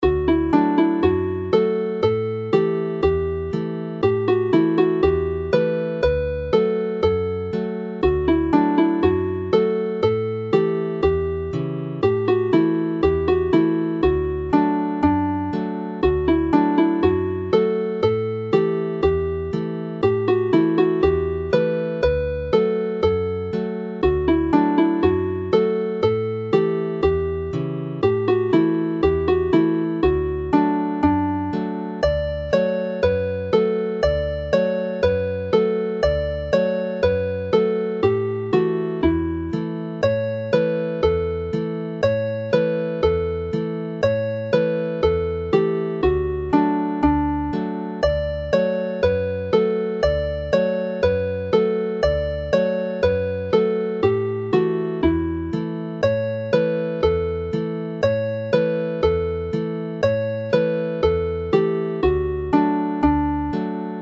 The new harp - key of D
Play the tune slowly